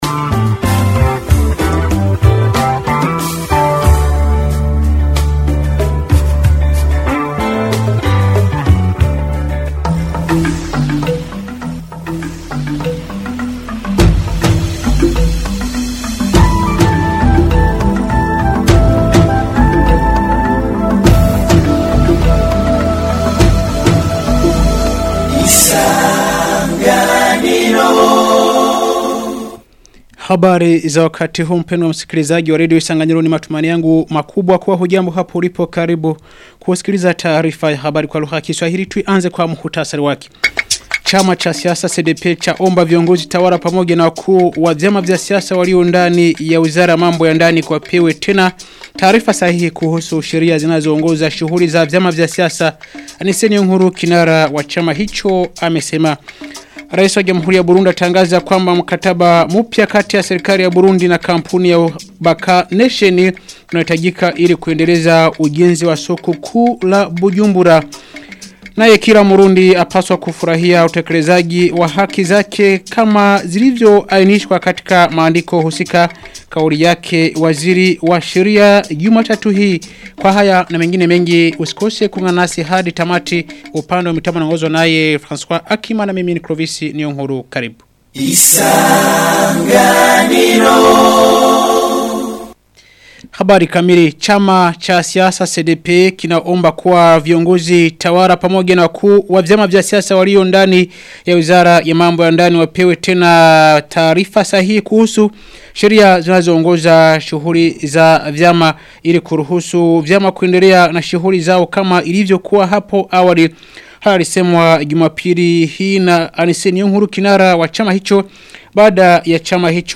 Taarifa ya habari ya tarehe 8 Disemba 2025